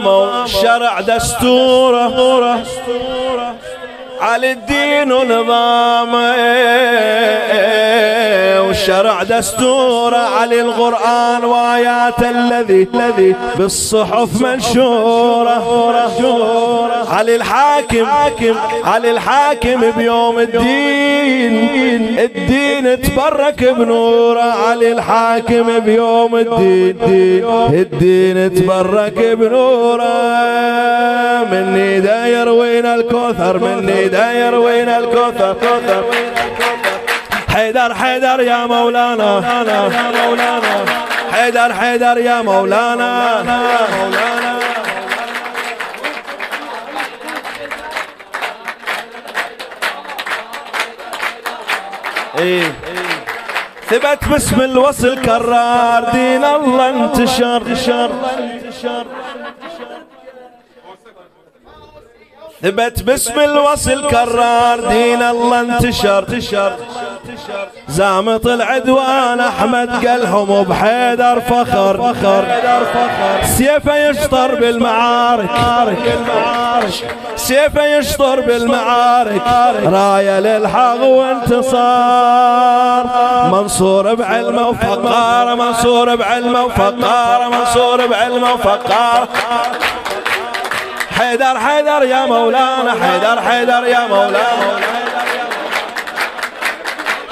هوسه عربی
ولادت امیر المومنین امام علی علیه‌السلام